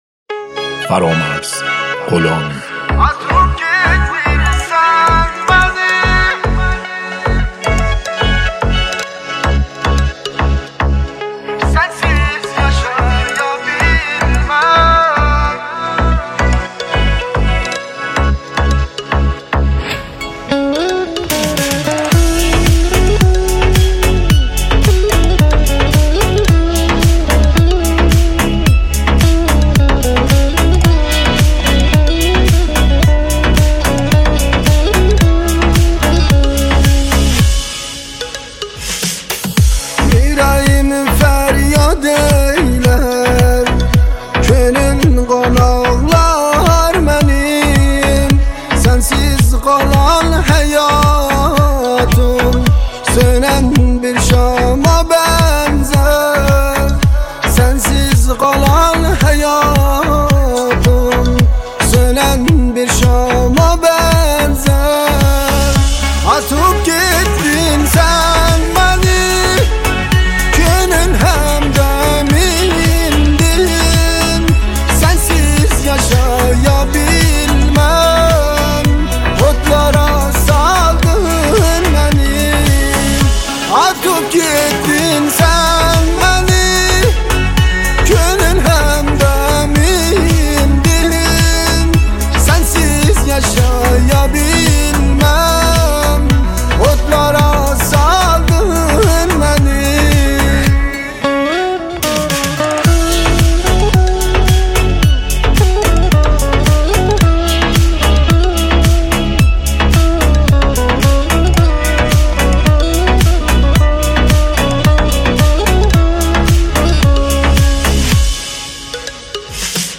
آهنگ آذری ، آهنگ ترکی